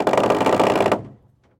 trebuchet_reloading_4.ogg